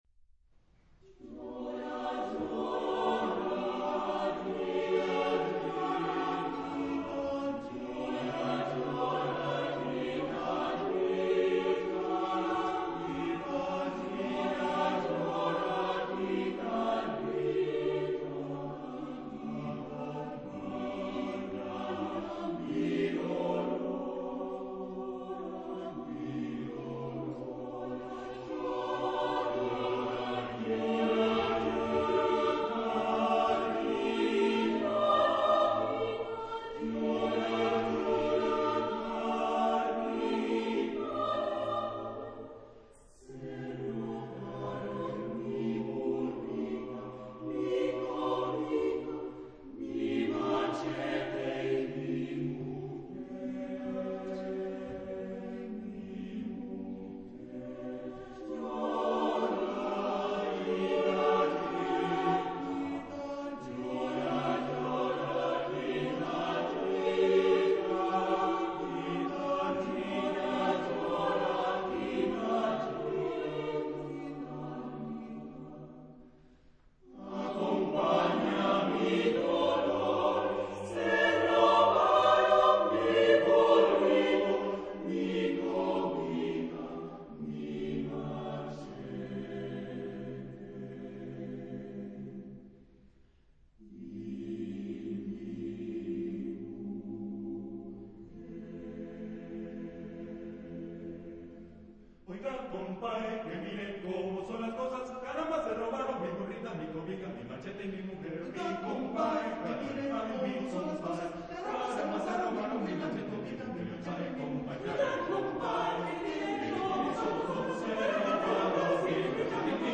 Genre-Style-Forme : Joropo ; Latino-américain ; Profane
Caractère de la pièce : joyeux ; nostalgique ; lent
Type de choeur : SATB  (4 voix mixtes )
Tonalité : sol mineur
Réf. discographique : Internationaler Kammerchor Wettbewerb Marktoberdorf